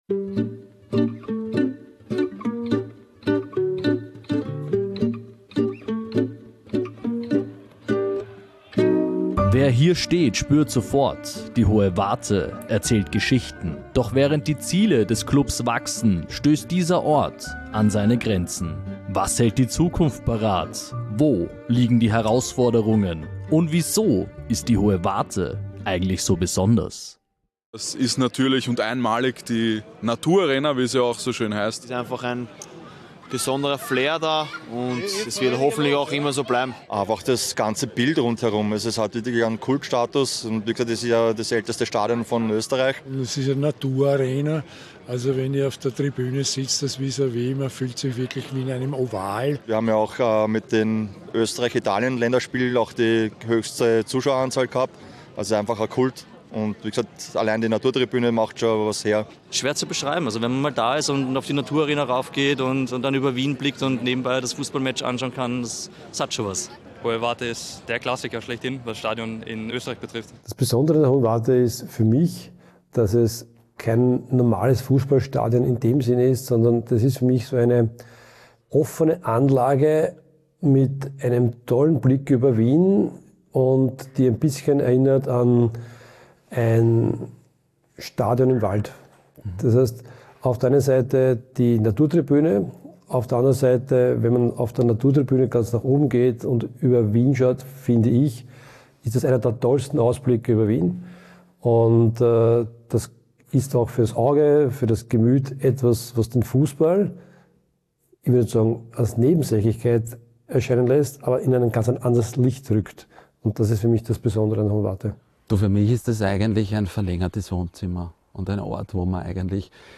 Eine Doku über Erinnerungen, Veränderungen und die Frage, wie viel Vergangenheit ein Stadion behalten kann.